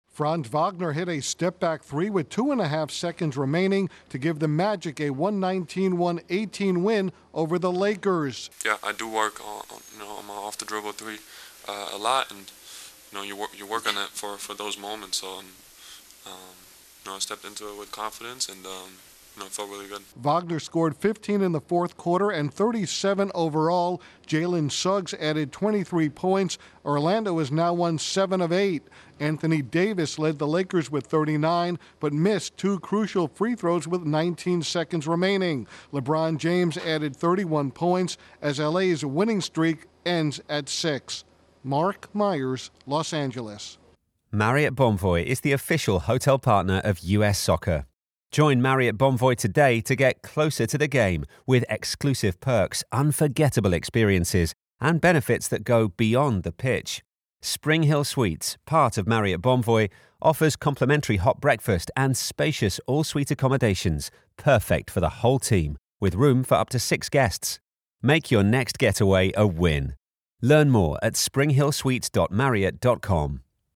The Magic got a clutch three by Franz Wagner in the closing seconds to beat the Lakers, 119-118. Correspondent